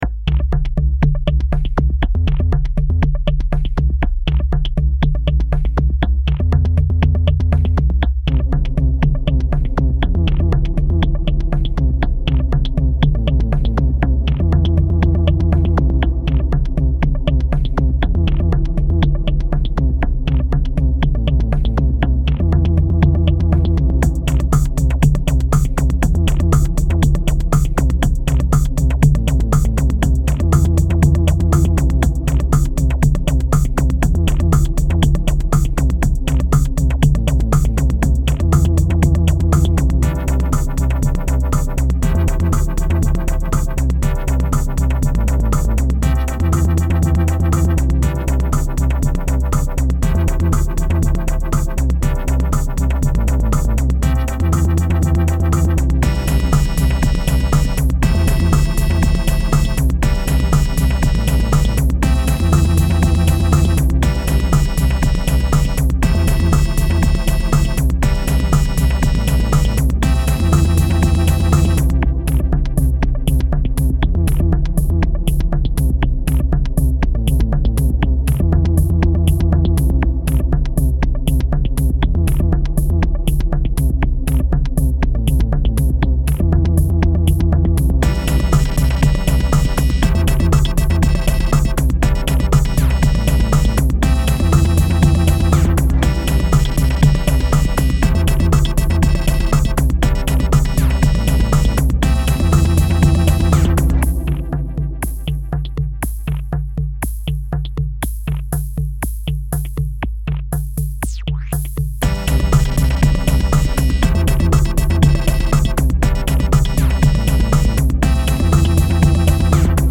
I made this in ableton and used the glitch plugin on a couple of the tracks to break it up a bit. This song has like a gazillian tracks for drums, for whatever reason I just felt that stacking them up sounded good. with not much time left, I decided to try to have some actual music to it.
So that actually doesn't kick in until like half way through the song, but alas here it is! enjoy :)
Filed under: Instrumental Song | Comments (3)
Keep up the glitching.